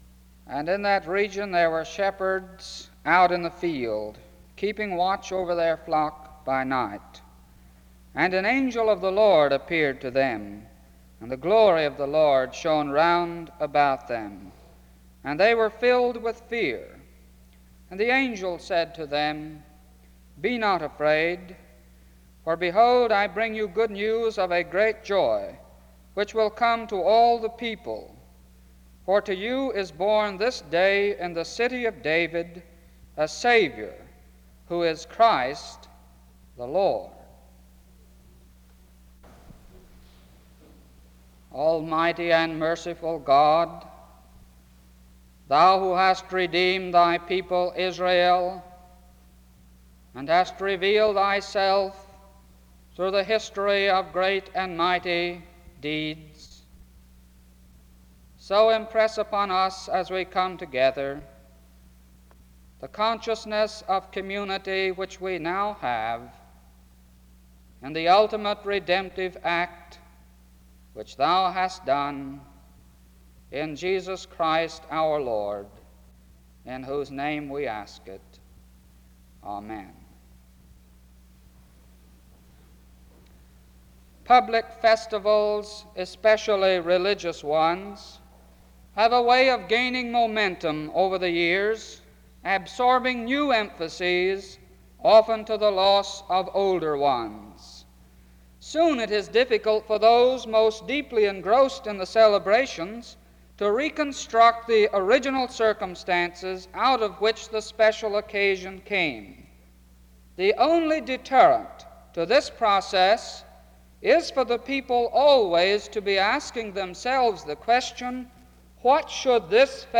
Subject Bible. Luke Christmas
SEBTS Chapel and Special Event Recordings SEBTS Chapel and Special Event Recordings